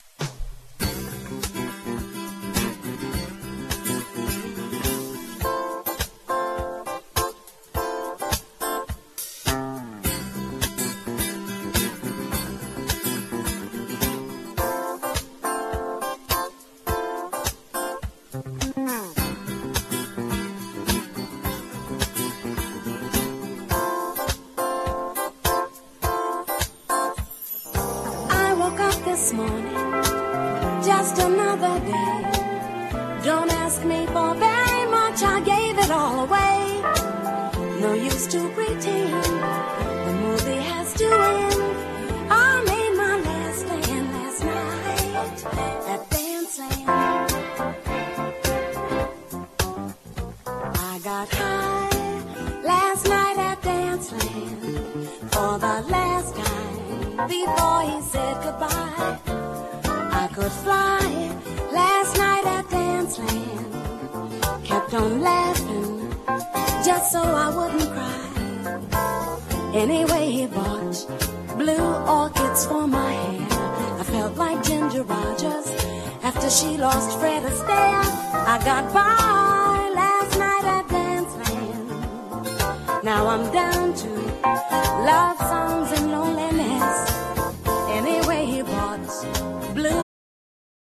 ソウルフルで艶やかなボーカルとコンテンポラリーな演奏がハマった隠れ名盤でもあります。